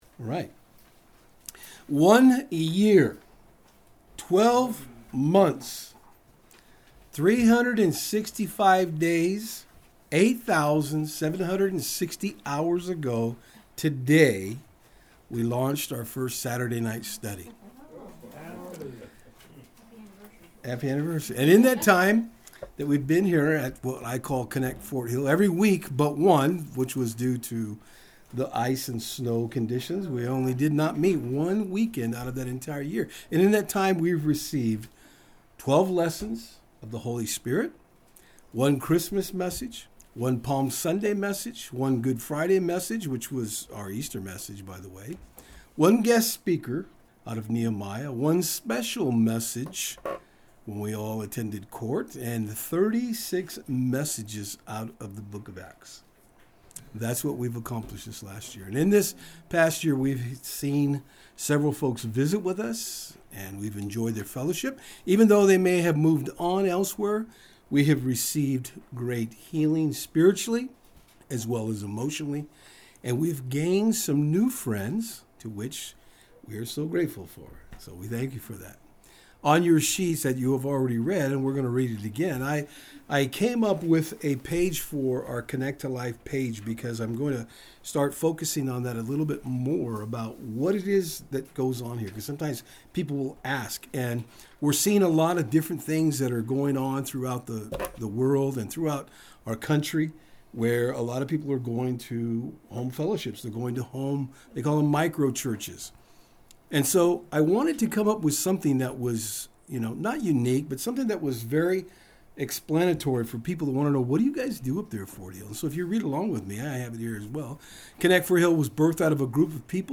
Today you will hear in part from the folks who have been with us how God has changed them, strengthened them, and has grown them by His word.
Service Type: Saturdays on Fort Hill